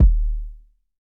BattlecatKick4.wav